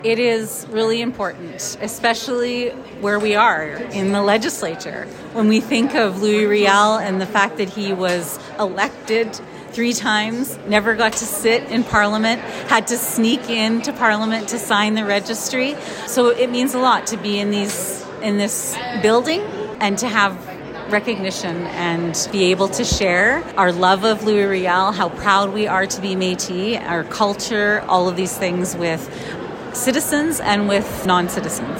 Otipemisiwak Métis Government President Andrea Sandmaier spoke with CFWE, saying it’s an important day, reflecting on Louis Riel’s legacy and the opportunity to showcase Métis culture.
lois-riel-commemorative-ceremony-audio-clip-01.mp3